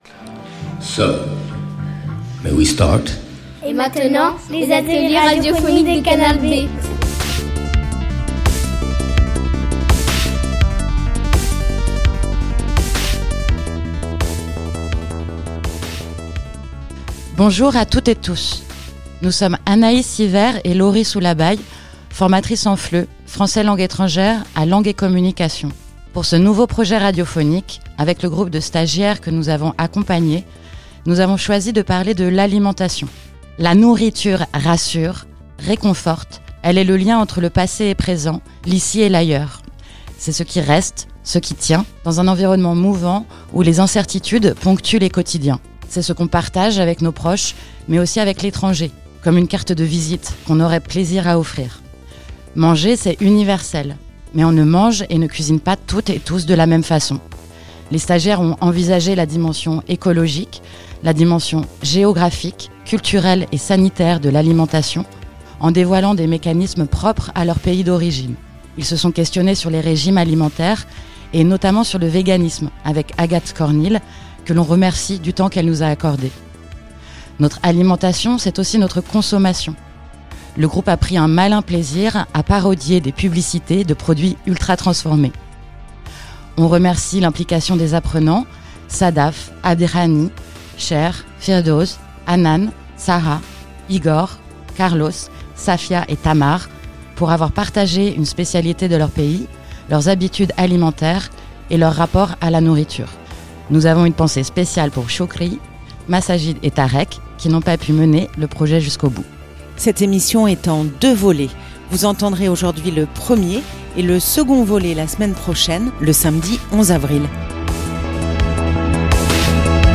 Les stagiaires de Langue et Communication vous proposent une émission autour de l’alimentation et de la cuisine du monde.